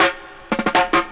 1st_Roll_160.wav